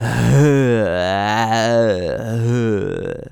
Male_High_Roar_03.wav